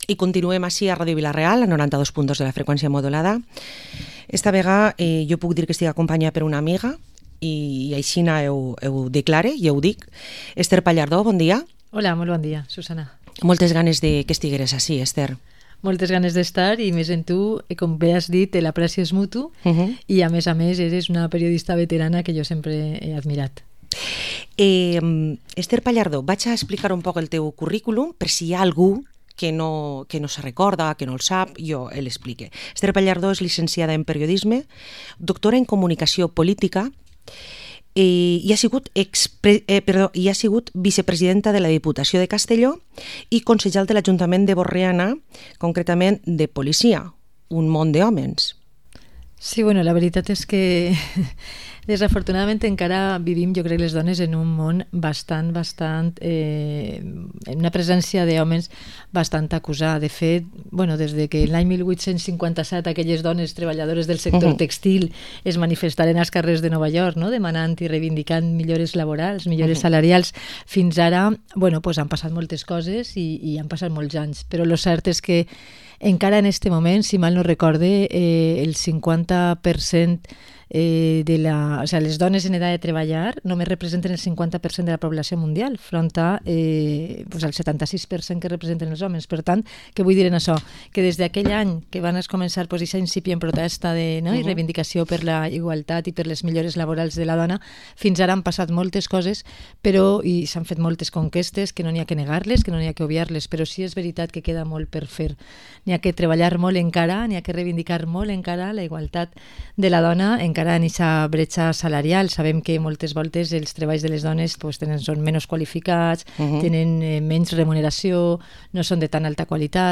Entrevista Esther Pallardó, empresaria, doctora en Comunicación Política y ex vicepresidenta de la Diputación de Castellón